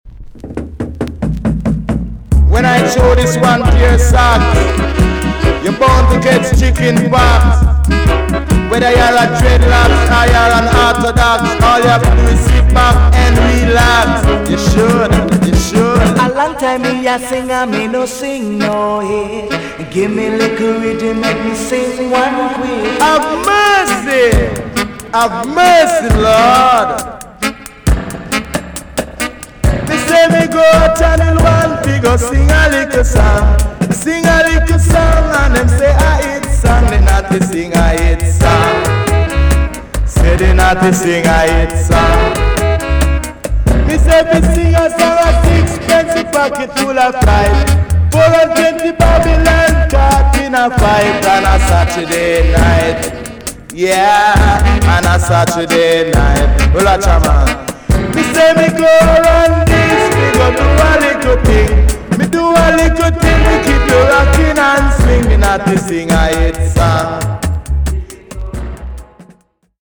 TOP >REGGAE & ROOTS
EX 音はキレイです。
1976 , RARE , WICKED ROOTS DJ TUNE!!